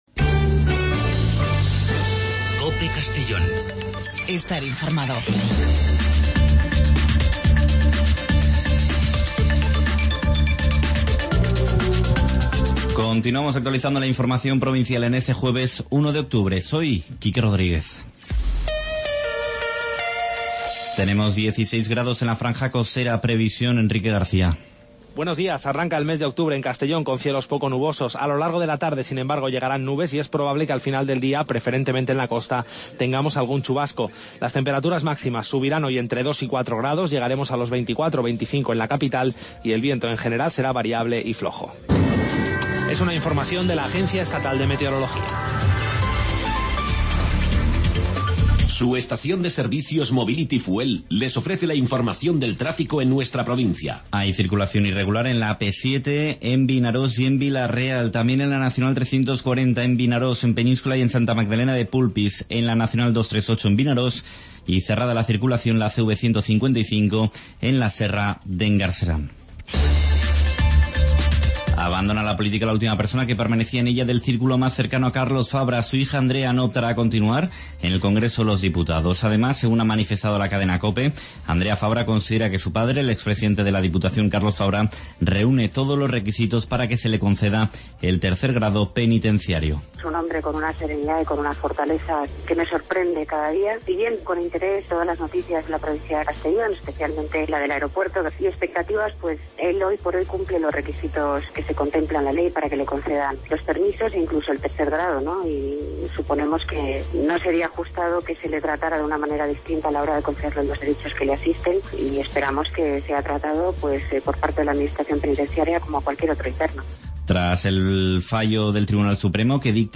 Informativo a nivel provincial con los servicios informativos de COPE en Castellón.